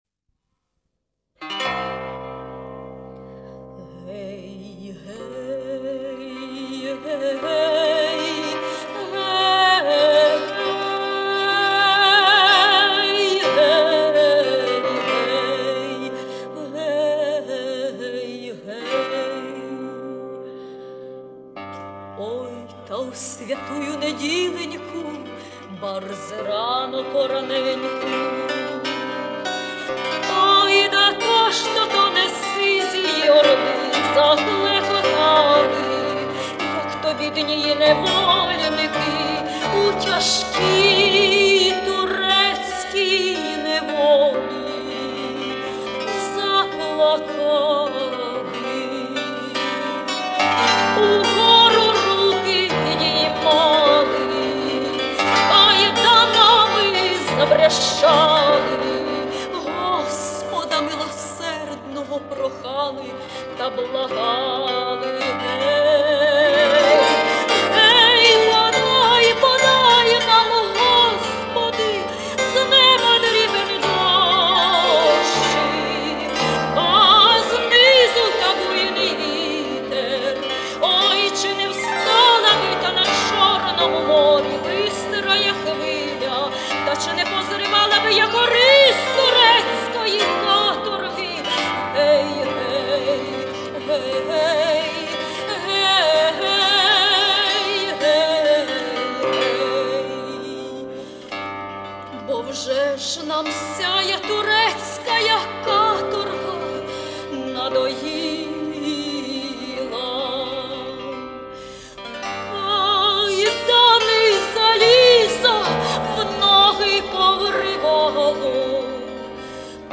дума XVI ст.